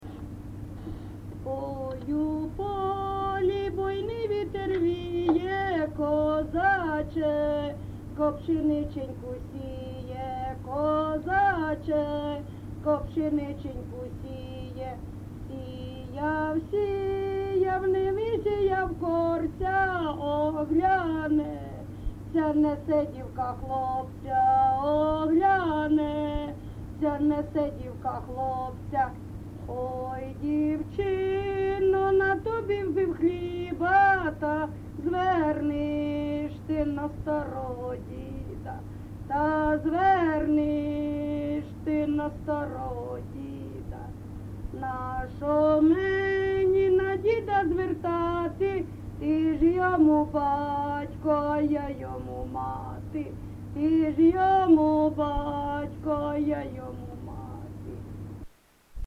ЖанрПісні з особистого та родинного життя
Місце записус. Гнилиця, Сумський район, Сумська обл., Україна, Слобожанщина